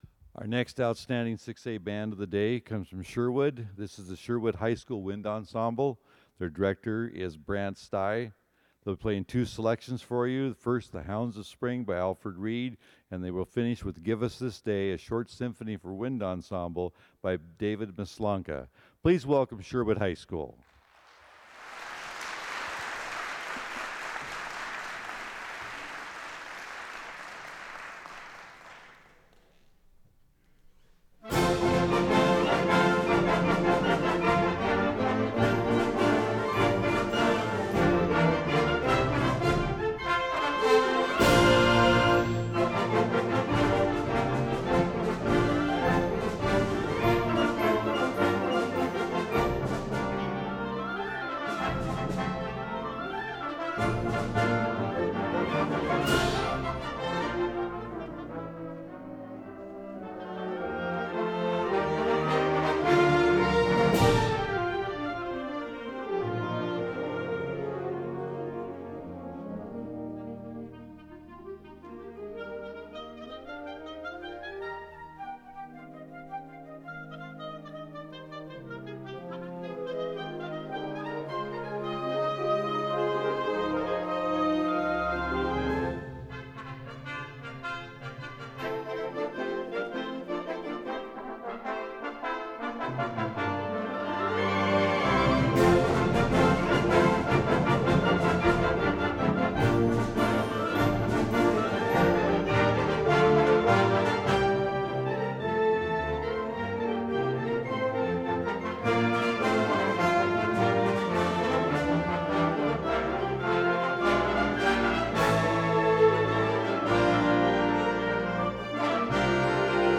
Wind Ensemble – OSAA 6A 2015 – 4th Place
Very inspiring & heartfelt performance on Saturday.
Recording: Wind Ensemble Performs: Hounds of Spring, Give Us This Day